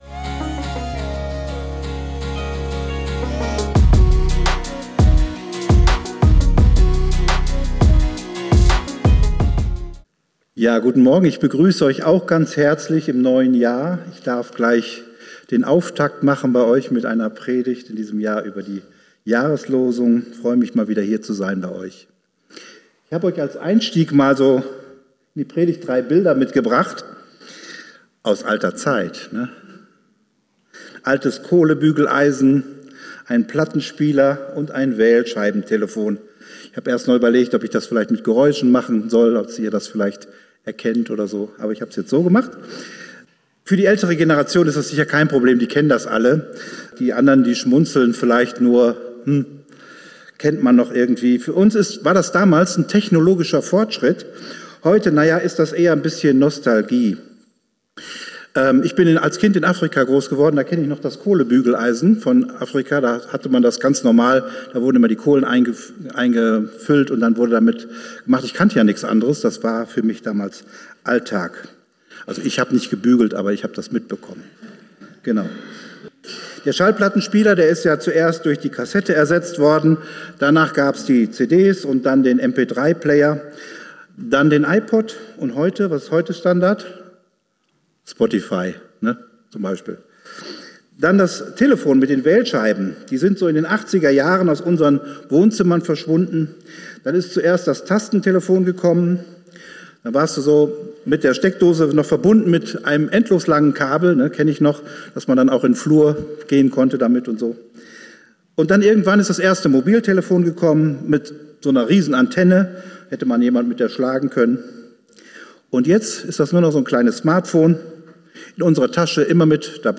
Früher war nicht alles Besser - Das Beste kommt noch ~ Geistliche Inputs, Andachten, Predigten Podcast